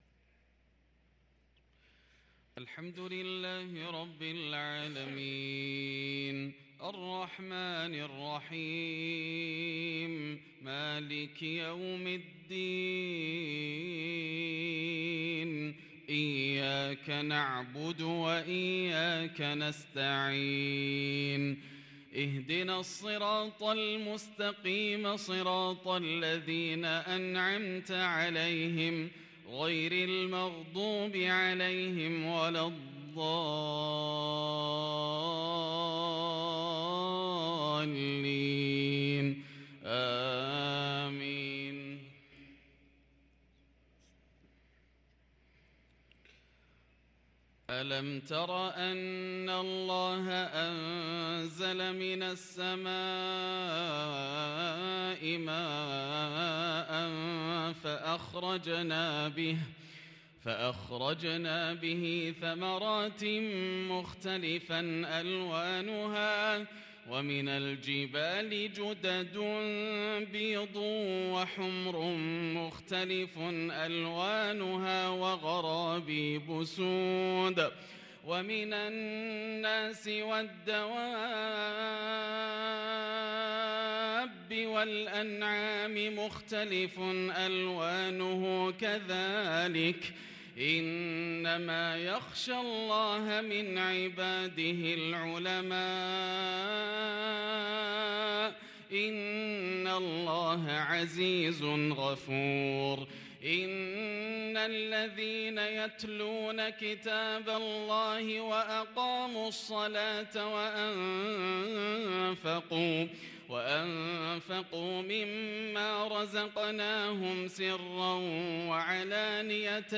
صلاة العشاء للقارئ ياسر الدوسري 5 صفر 1444 هـ
تِلَاوَات الْحَرَمَيْن .